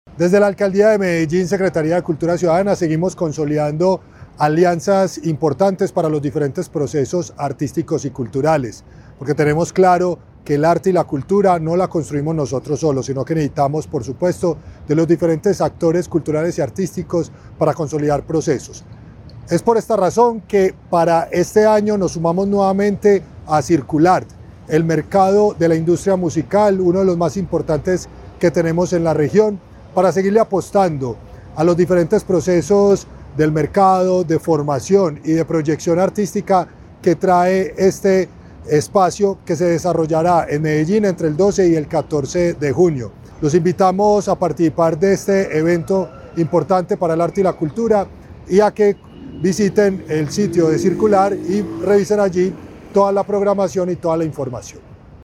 Audio Declaraciones del subsecretario de Arte y Cultura, Cristian Cartagena Del 12 al 14 de junio, la capital antioqueña será el escenario de la edición 16 de Circulart, un evento que impulsa el mercado musical.
Audio-Declaraciones-del-subsecretario-de-Arte-y-Cultura-Cristian-Cartagena-1.mp3